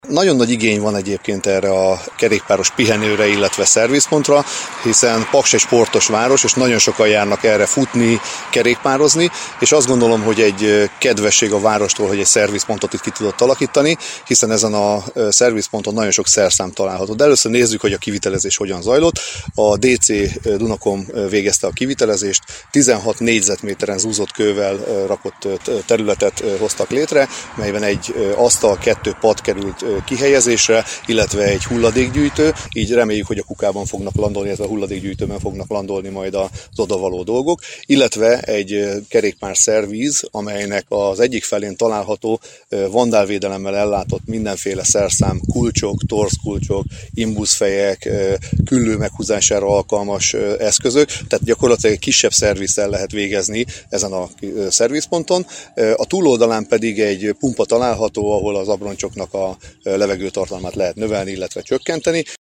A projekt részleteiről Molnár József a körzet önkormányzati képviselője beszélt rádiónknak.